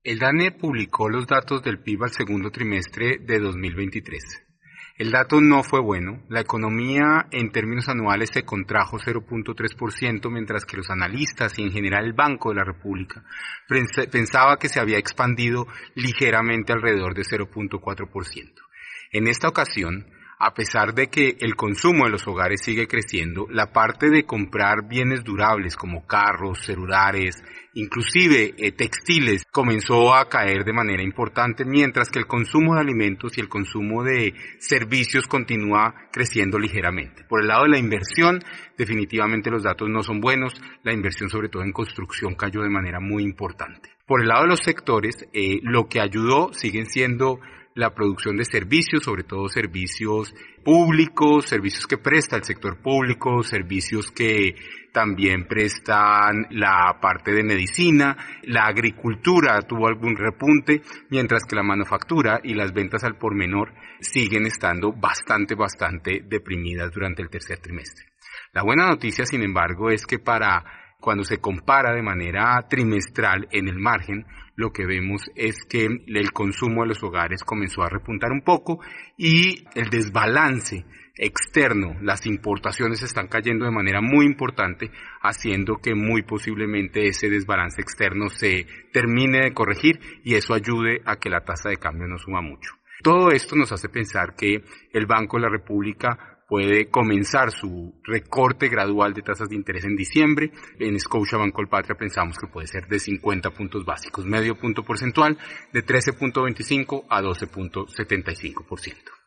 Análisis